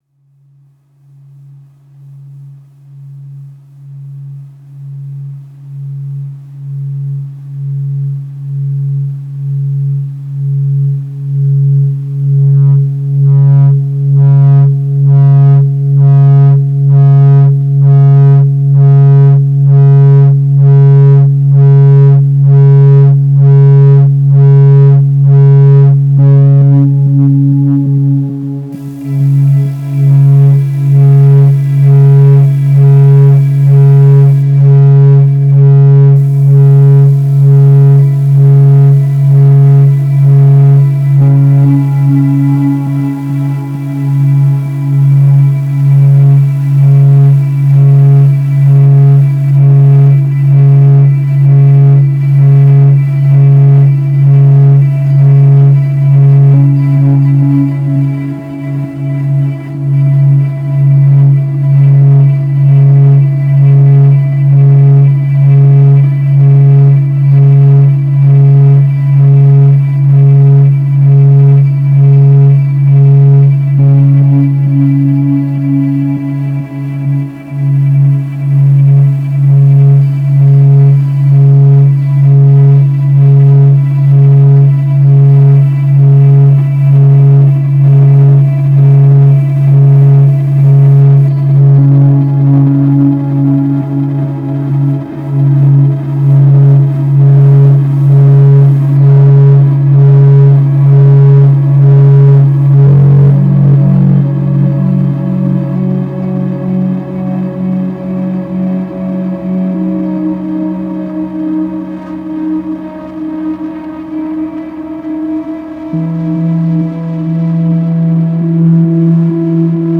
*фоновая композиция – Старуха Мха